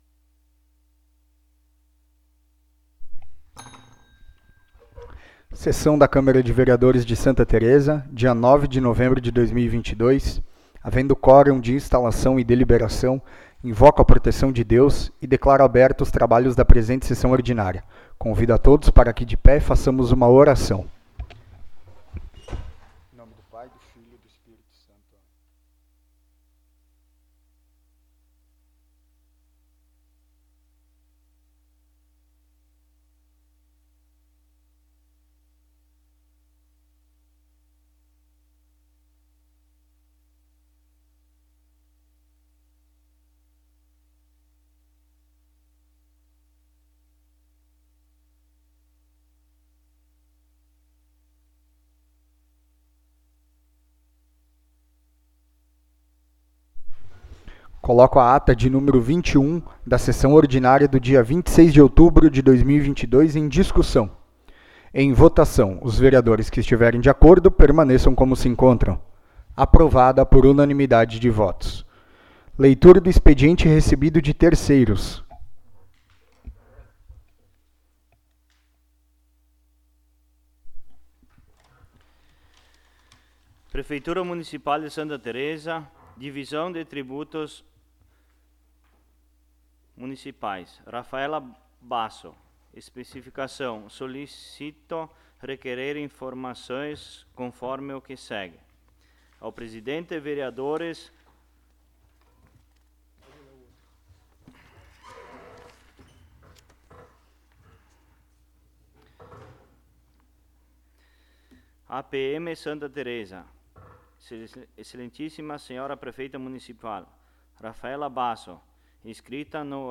19° Sessão Ordinária de 2022
Local: Plenário Pedro Parenti